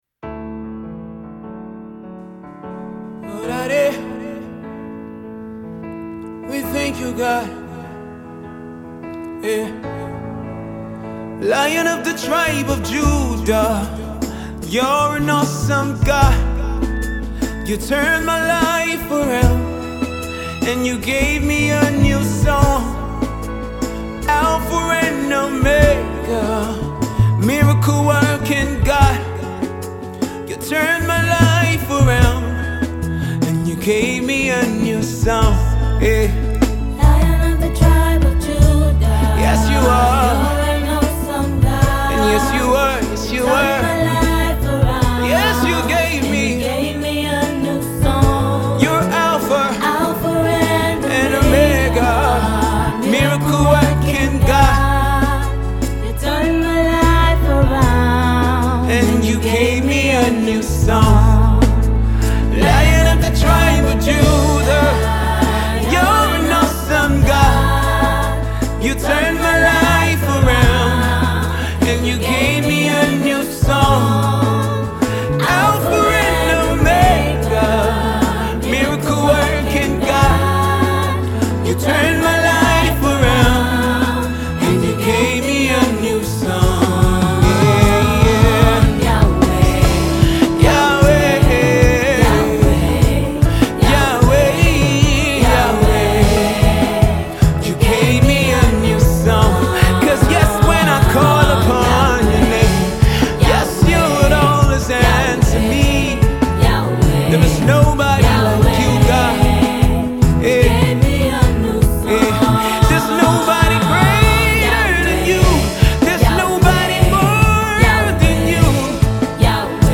easy to catch and worship along melody